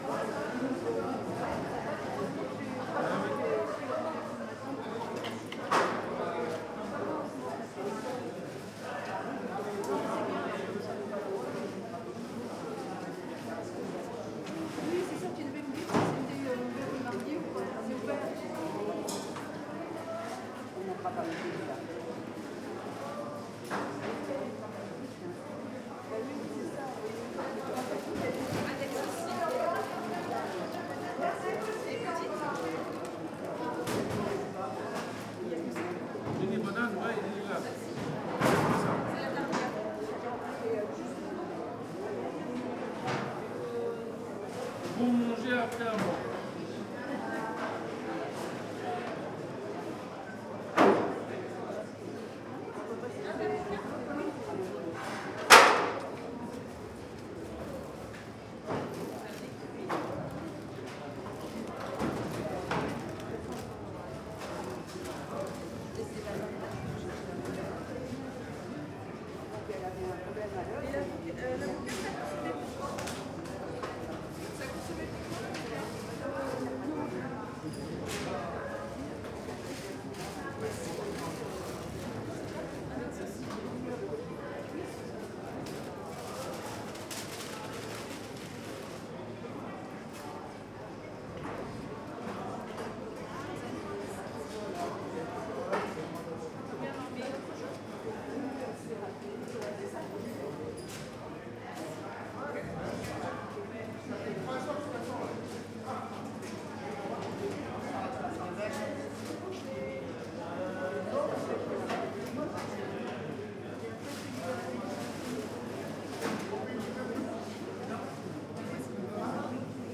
Henri-Barbusse covered market hall in Levallois-Perret. Few people.
Listen : Covered market #4 (173 s)